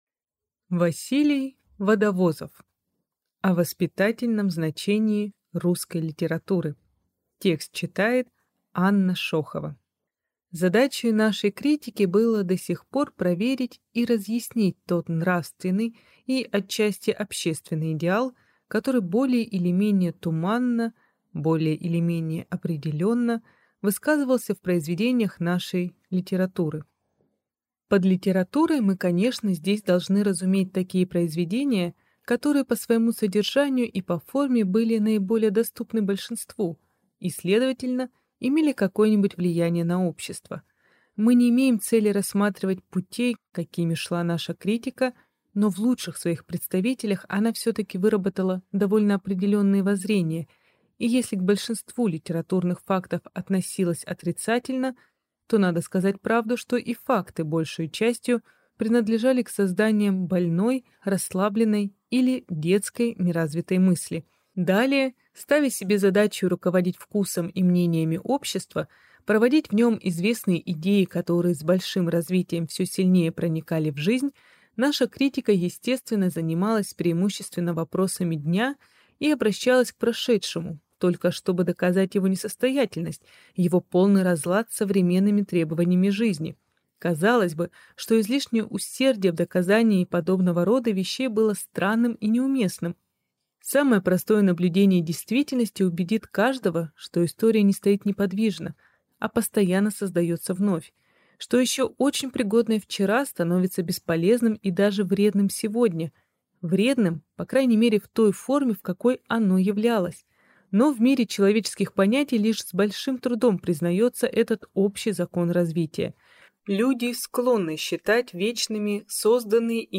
Аудиокнига О воспитательном значении русской литературы | Библиотека аудиокниг